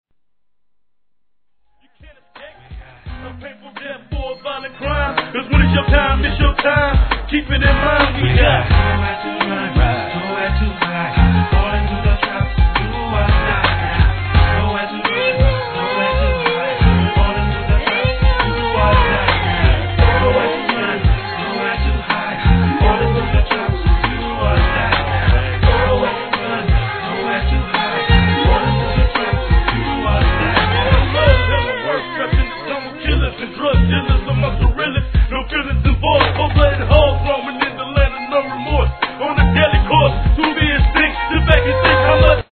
G-RAP/WEST COAST/SOUTH
哀愁漂うミディアム好FUNK